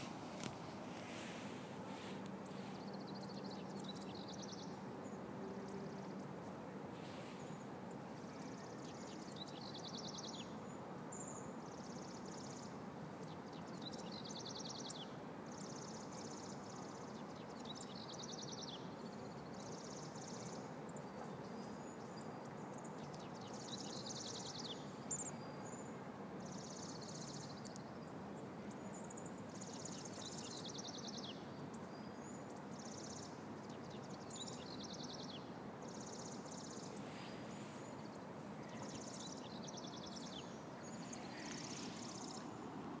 PAWR singing (belting?)